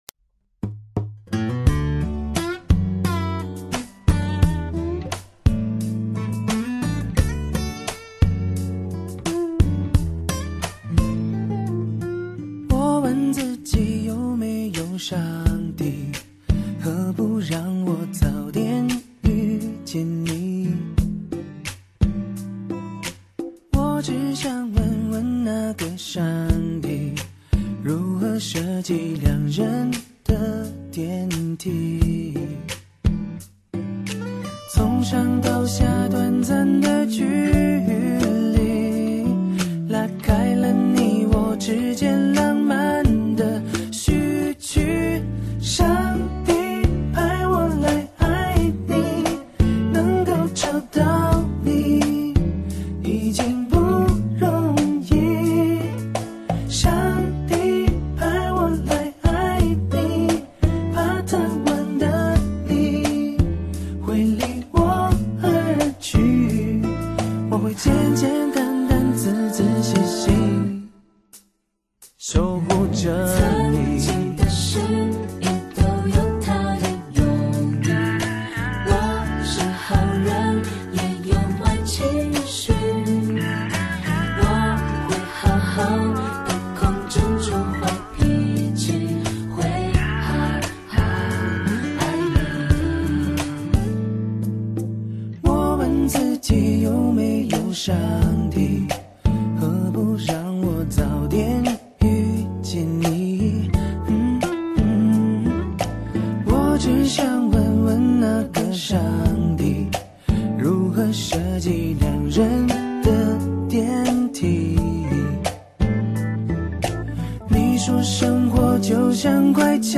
Ps：在线试听为压缩音质节选，体验无损音质请下载完整版 作曲